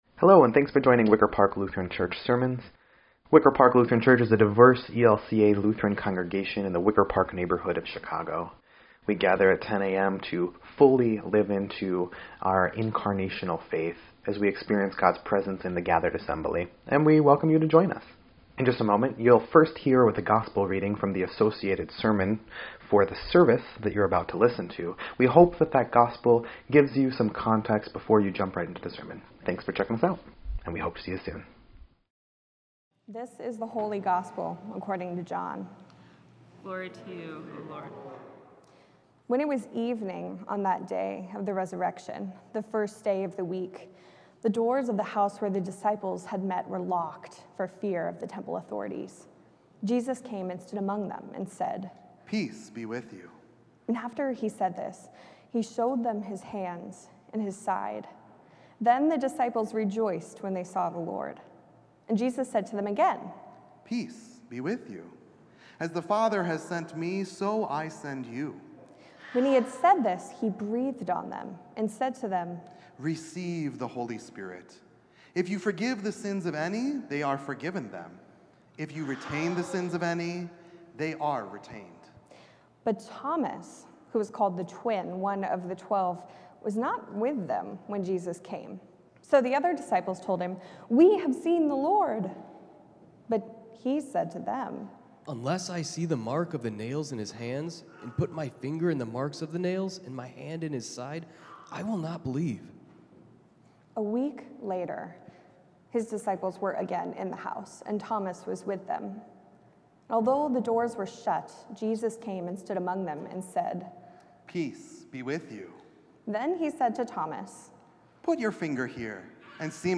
4.7.24-Sermon_EDIT.mp3